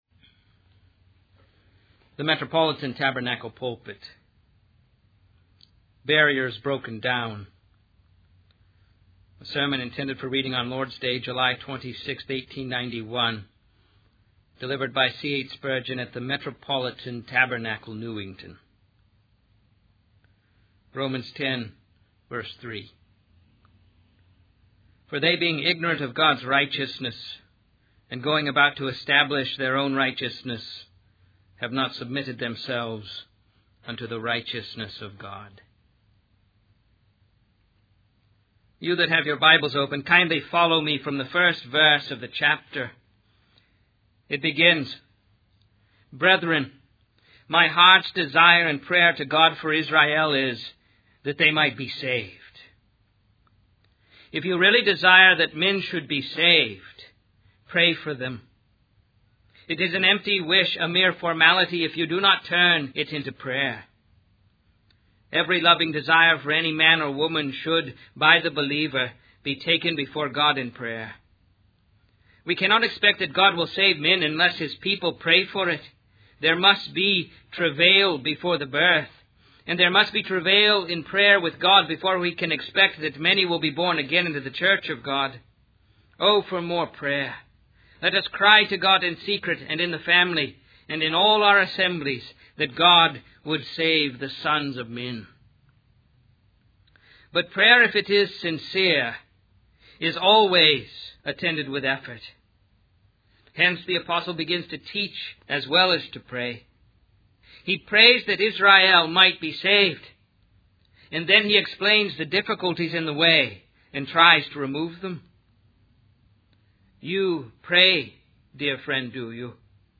The sermon transcript discusses the blessedness of Jesus Christ, who is described as God's only begotten Son, the Word made flesh. It emphasizes Jesus' sacrifice and salvation for humanity through his death on the cross.